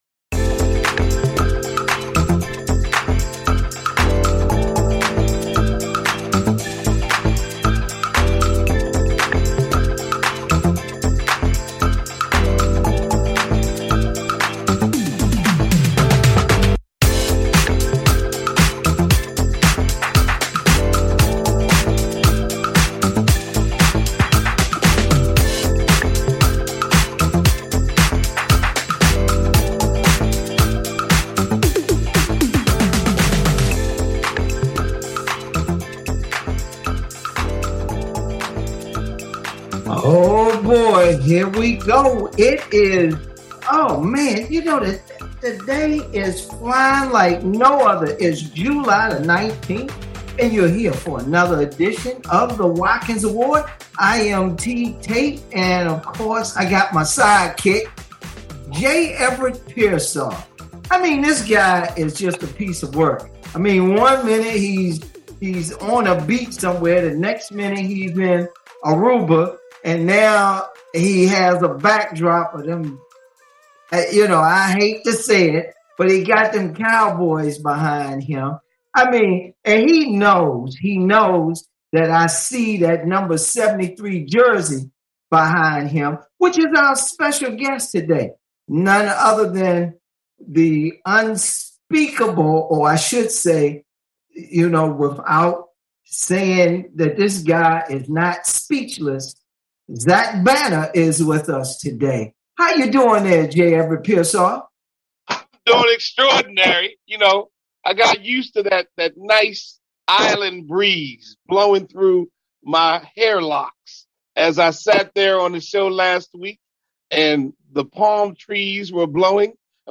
Guest, Zach Banner, NFL and former USC Academic All American and 2 sport athlete